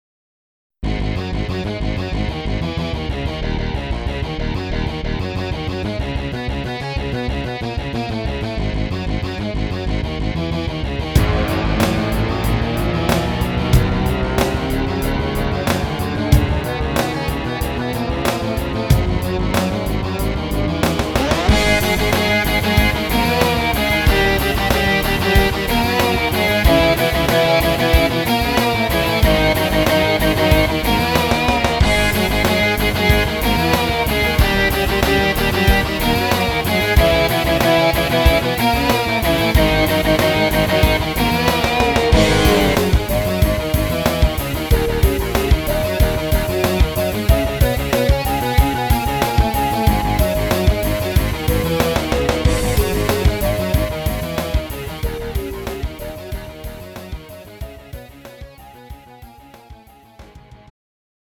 음정 -1키
장르 pop 구분 Lite MR